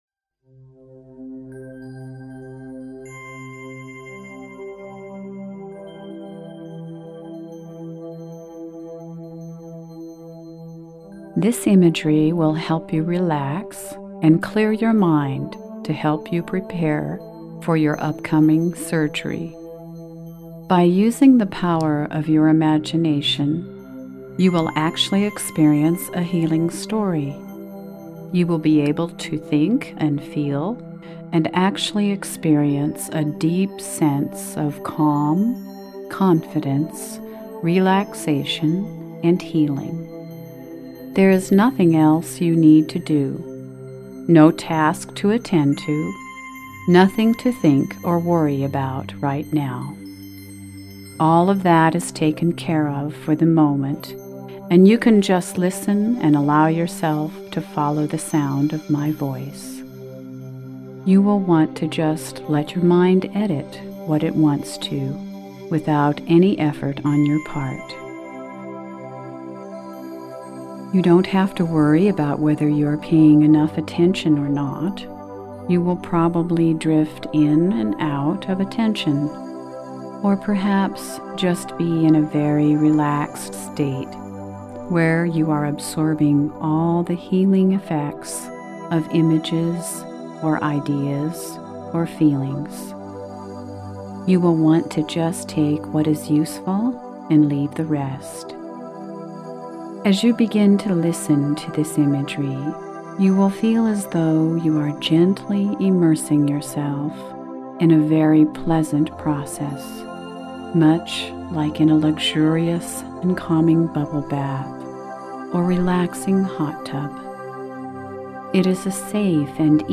Guided Imagery - Before Surgery ACL
People often describe the session as being very relaxing.